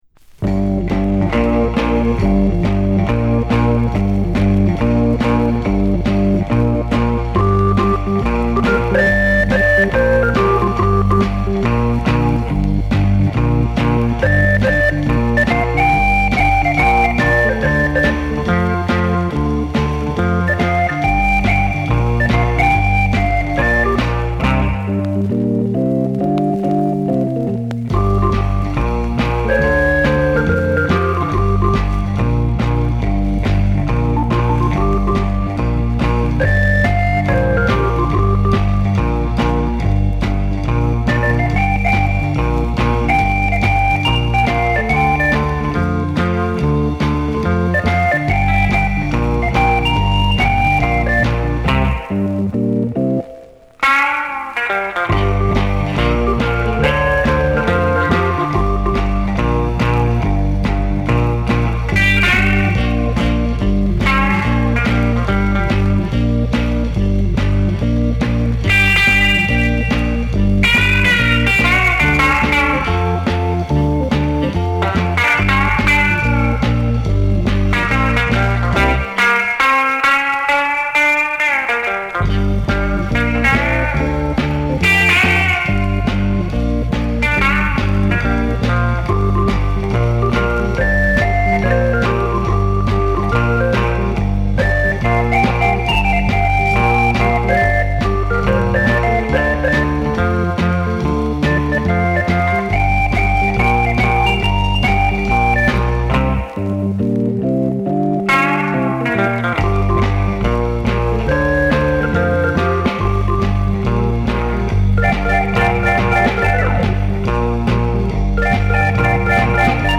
[Comped] [Popcorn] [Exotica] [NEW]